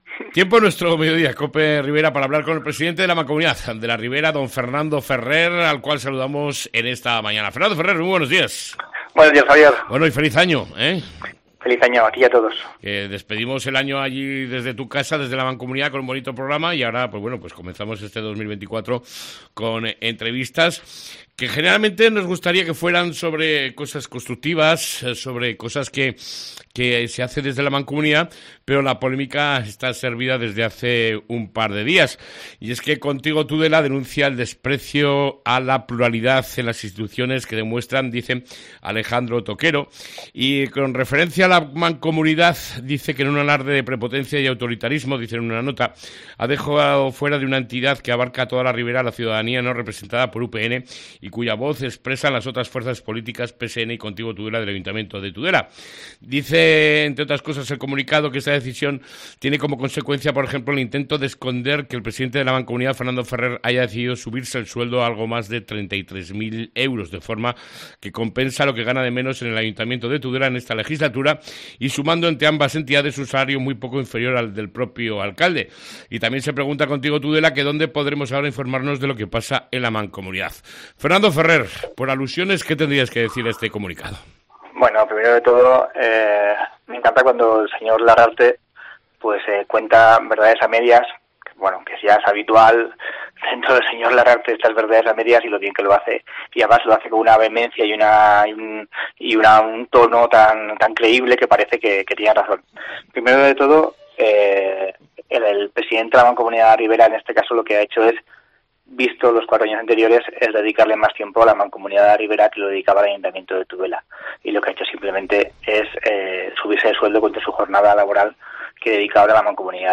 ENTREVISTA CON EL PRESIDENTE DE LA MANCOMUNIDAD, FERNANDO FERRER